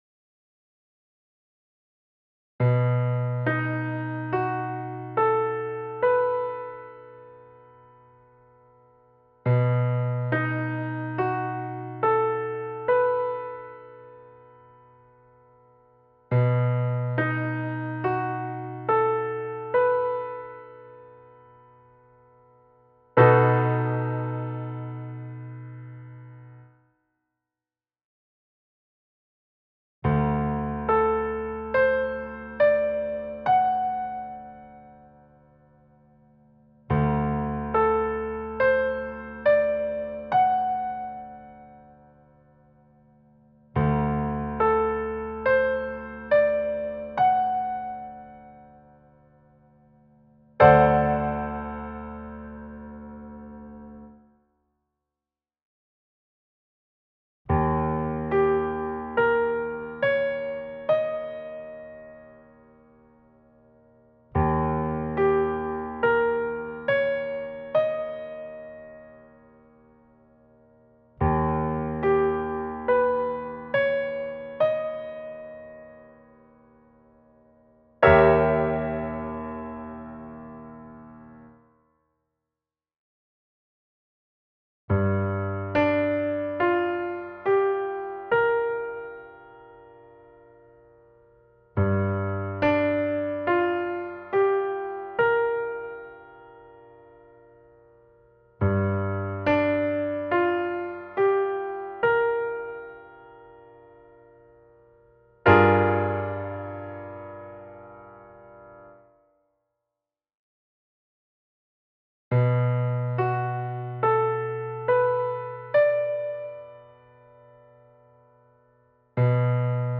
Riconoscimento e identificazione degli arpeggi(quadriadi in 1°-2°-3° rivolto)